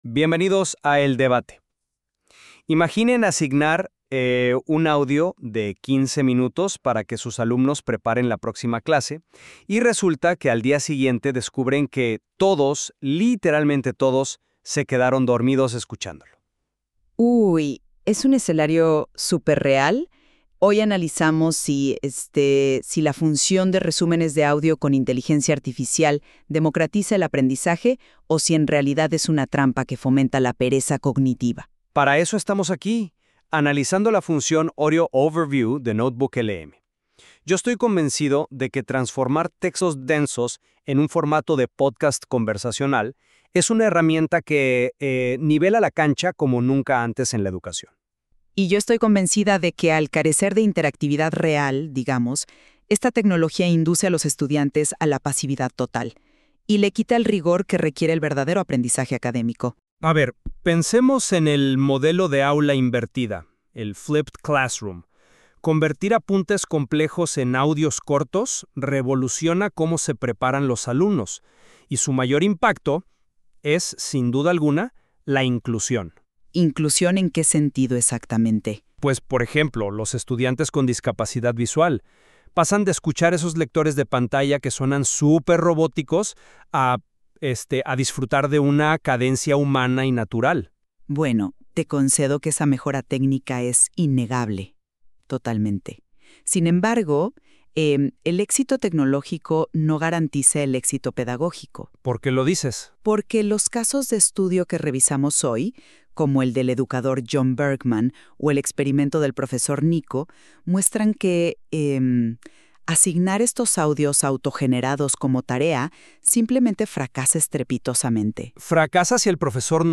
El «Audio Overview» no es un lector de texto (Text-to-Speech); es una síntesis y reescritura total del material en formato de podcast. La IA lee todo el cuaderno, identifica las ideas principales, los debates ocultos y las aplicaciones prácticas, y luego redacta un guion donde dos presentadores de IA (conversacionales y empáticos) discuten y explican el material.